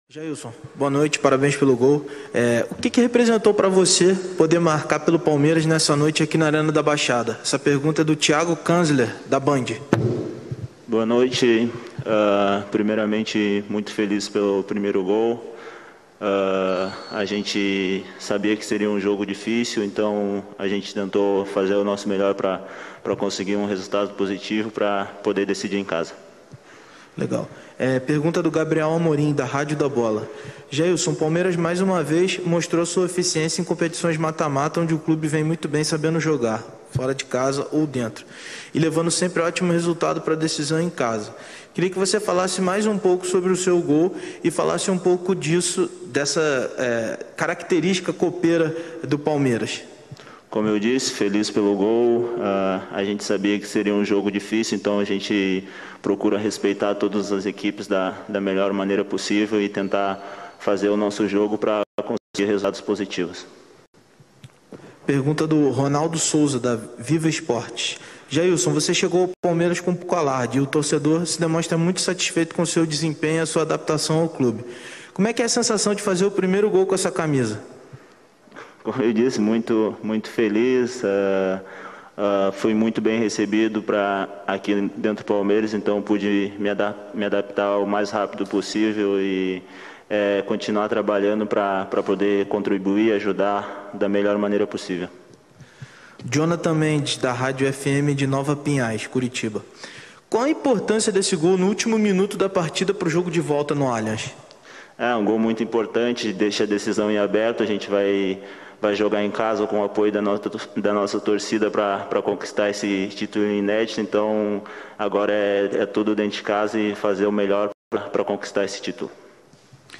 COLETIVA-ABEL-FERREIRA-E-JAILSON-_-ATHLETICO-X-PALMEIRAS-_-RECOPA-SULAMERICANA-2022-1.mp3